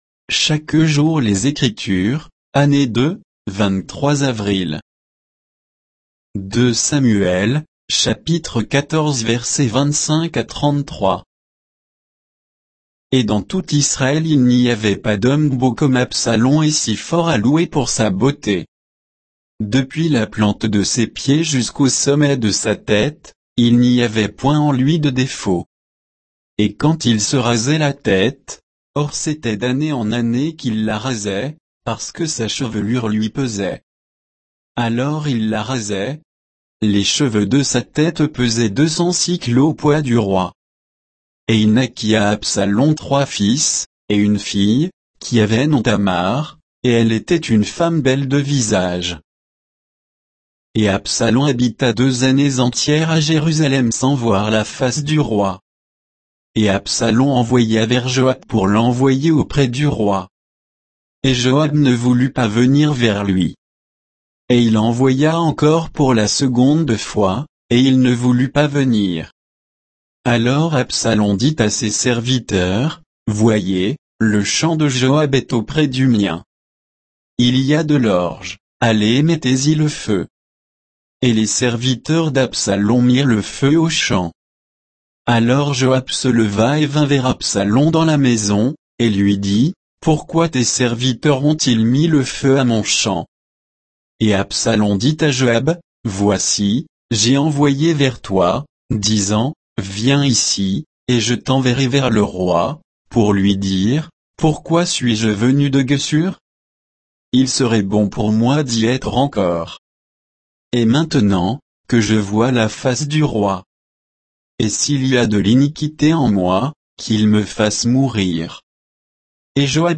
Méditation quoditienne de Chaque jour les Écritures sur 2 Samuel 14